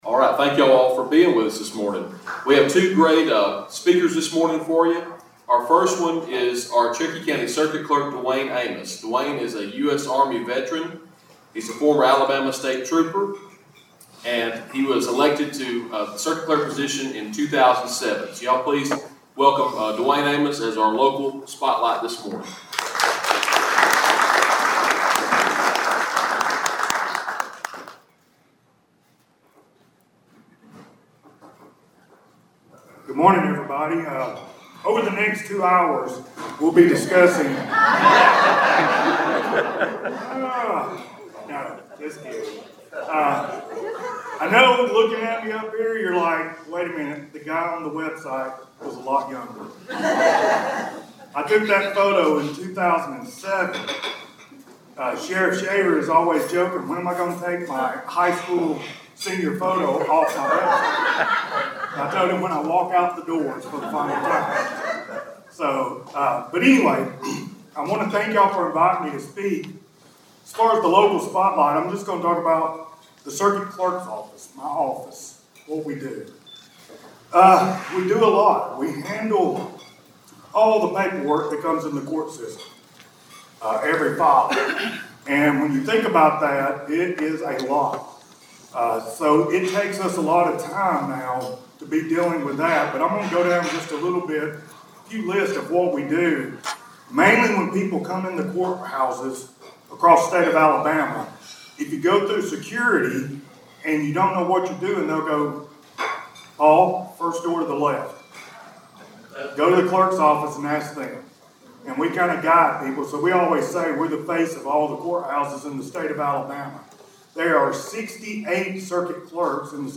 It was a HUGE day Saturday at the Easy Street Restaurant & Bar in Centre (located at 1605 West Main Street), as the Cherokee Country Republican Party hosted their regular quarterly Breakfast Event (on April 27th).
Amos spoke on a number of different items, including his role as, and the duties of, being Circuit Clerk for Cherokee County.